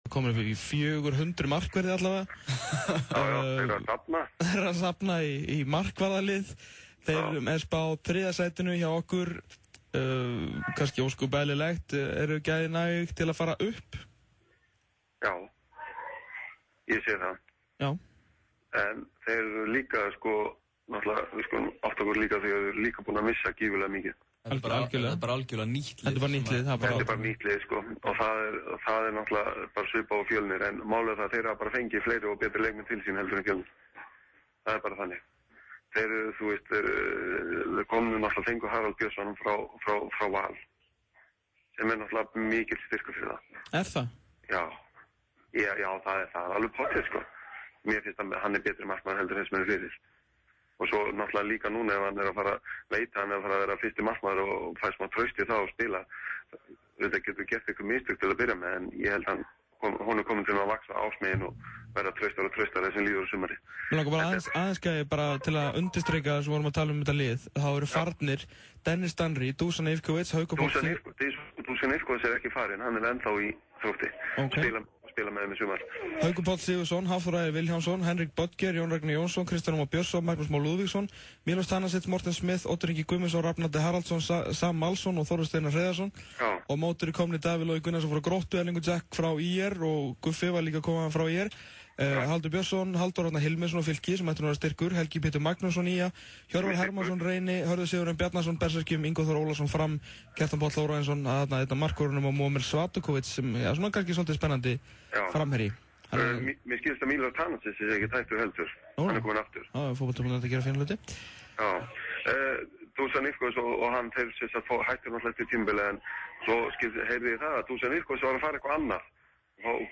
62. þáttur útvarpsþáttarins
í viðtali